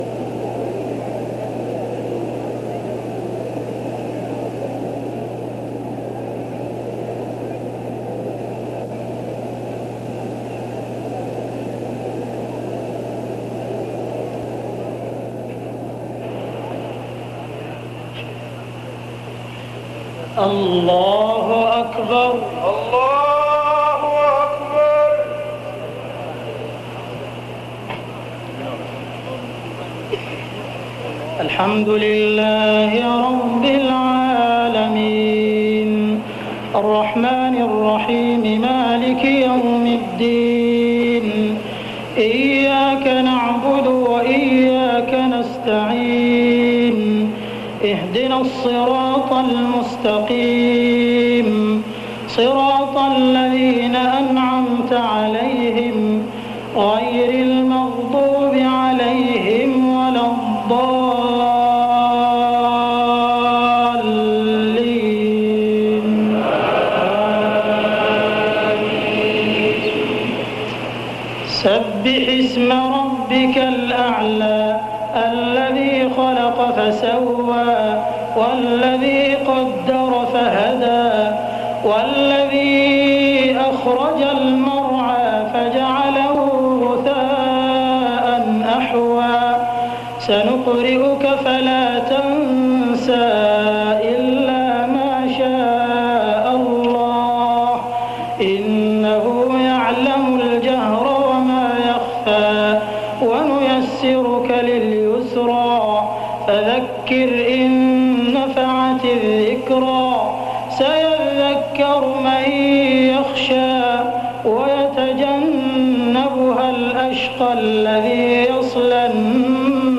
تراويح ليلة 29 رمضان 1411هـ من سورة الأعلى الى الكوثر Taraweeh 29 st night Ramadan 1411H from Surah Al-A'laa to Al-Kawthar > تراويح الحرم المكي عام 1411 🕋 > التراويح - تلاوات الحرمين